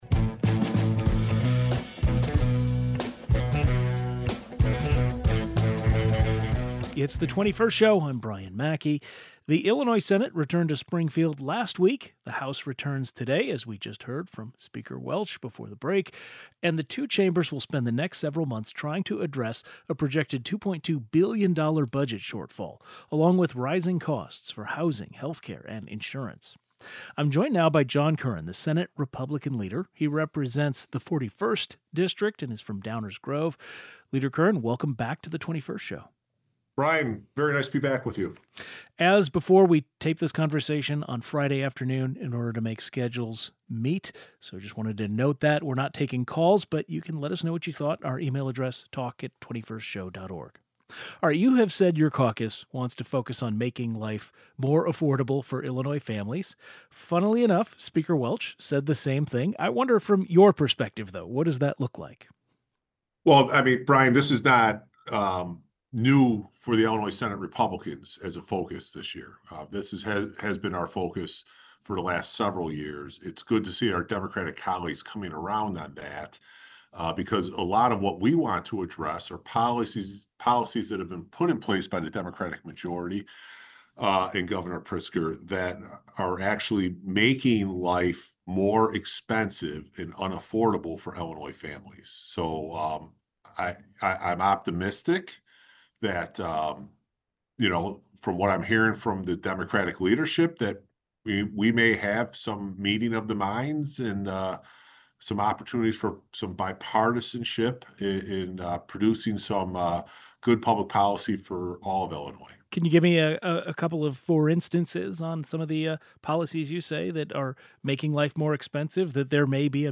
Senate Republican Leader John Curran joins the program to highlight some goals Republican lawmakers are focusing on this session. The 21st Show is Illinois' statewide weekday public radio talk show, connecting Illinois and bringing you the news, culture, and stories that matter to the 21st state.